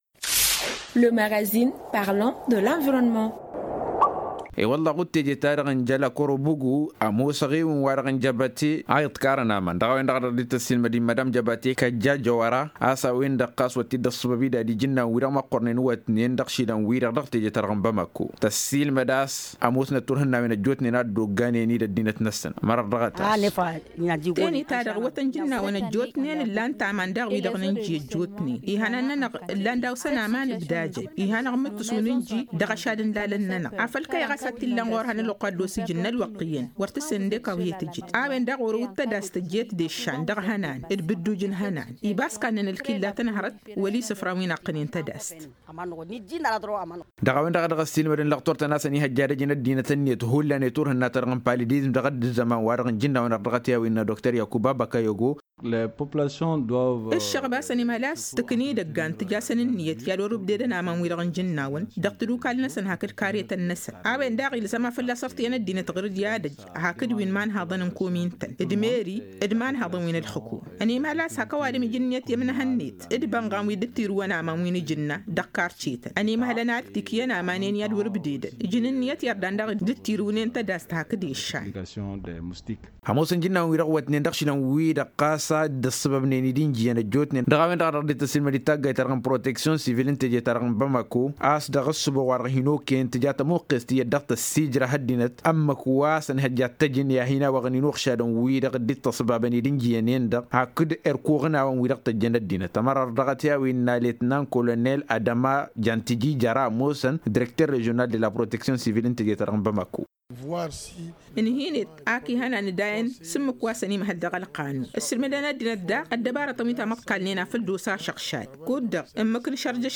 Magazine en tamasheq: Télécharger